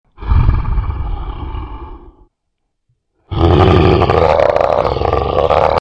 Download Free Bigfoot Sound Effects
Bigfoot